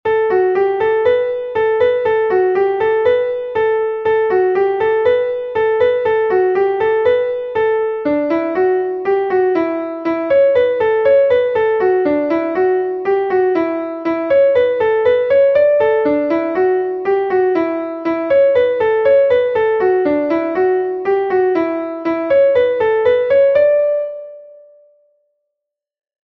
Laridé de Bretagne